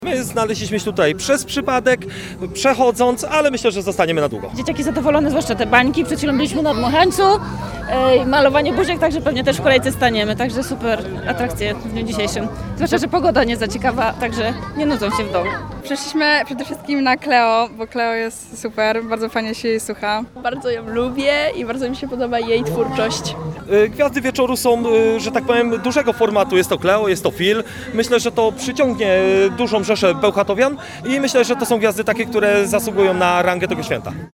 ludzie-o-dniu-energetyka.mp3